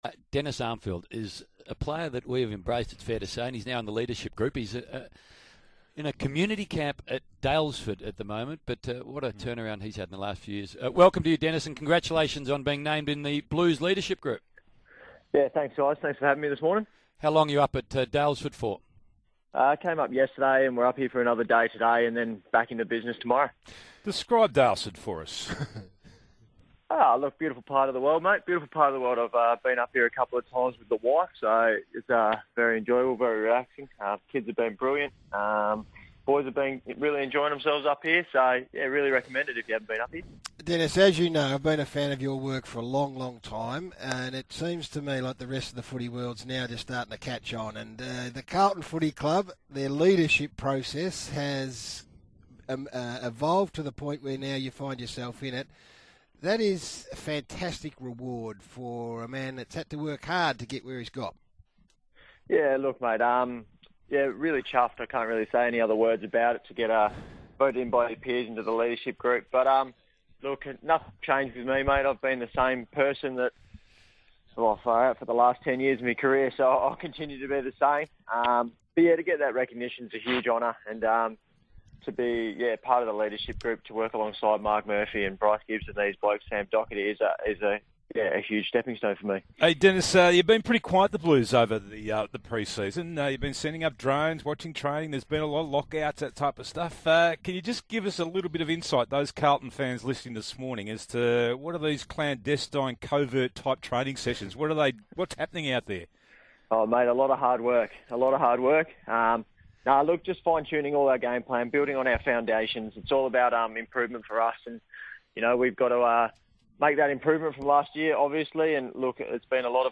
Carlton forward Dennis Armfield speaks to SEN 1116 during the Blues' Community Camp.